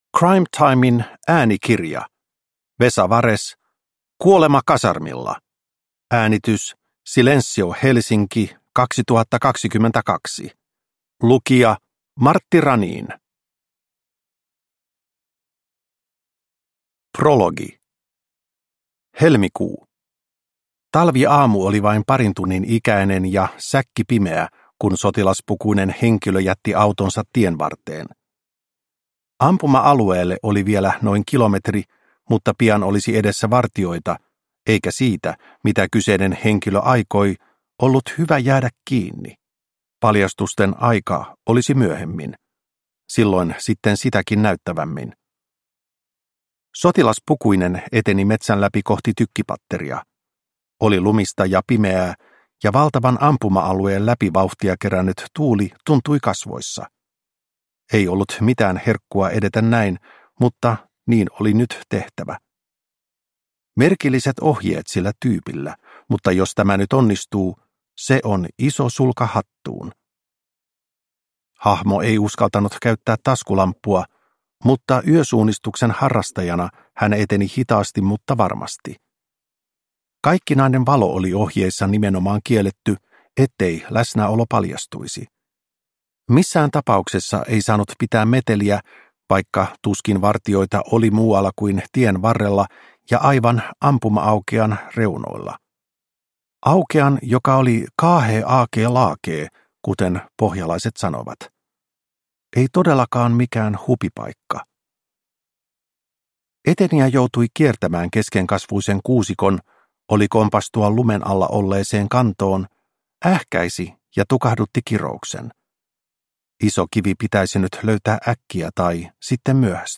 Kuolema kasarmilla – Ljudbok – Laddas ner